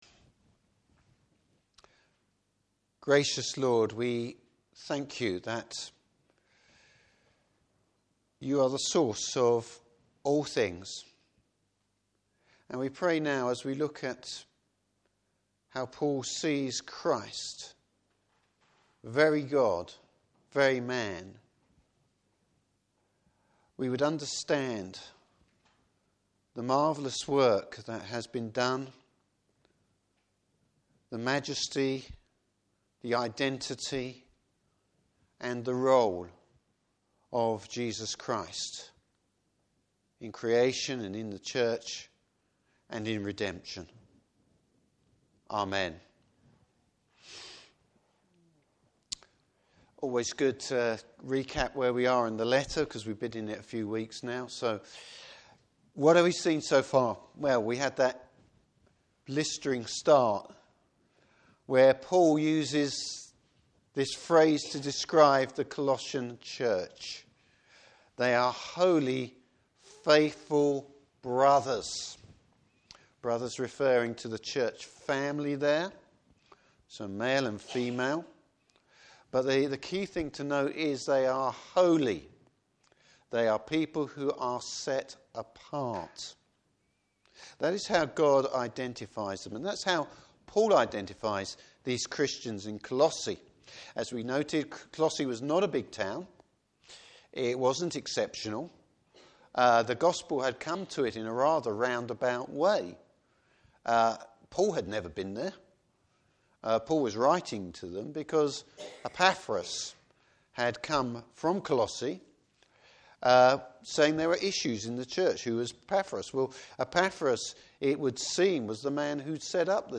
Service Type: Morning Service The role of Christ in creation, the Church and redemption.